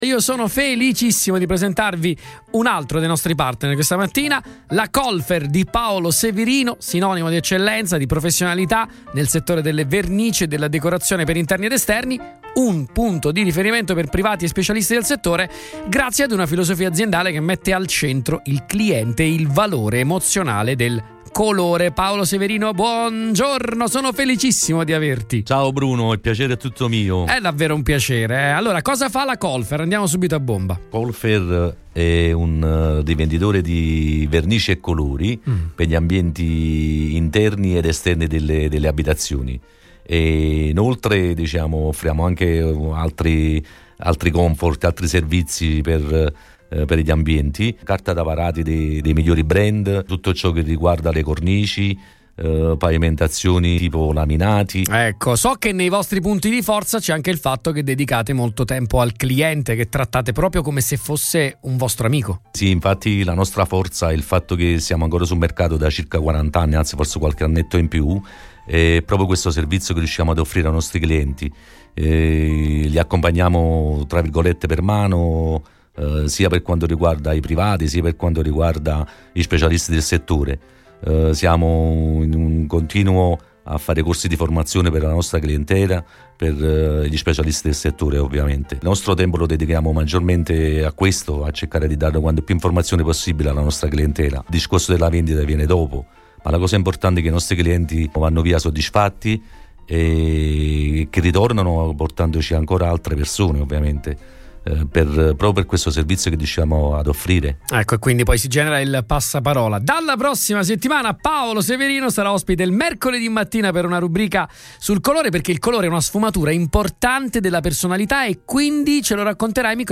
Durante un’intervista in studio con Radio Punto Nuovo